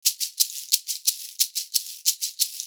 Index of /90_sSampleCDs/USB Soundscan vol.36 - Percussion Loops [AKAI] 1CD/Partition A/10-90SHAKERS